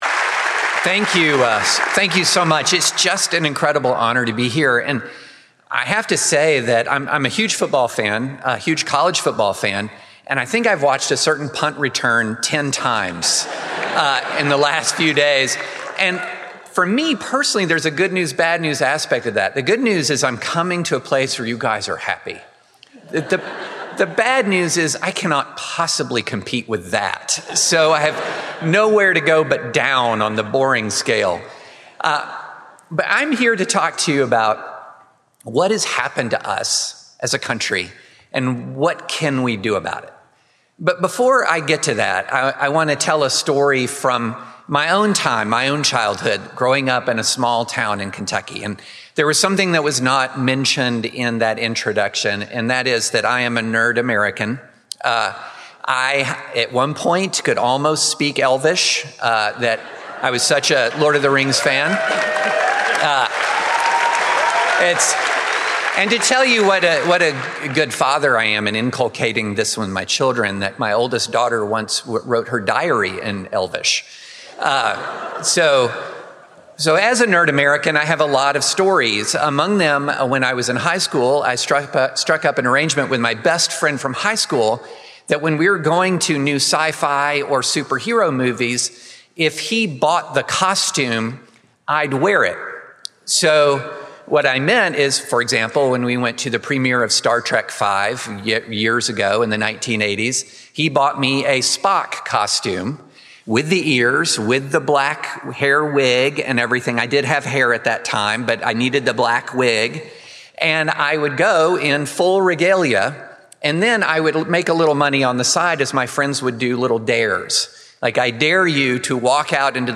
David French, a columnist for the New York Times and bestselling author, delivered this forum address on September 24, 2024.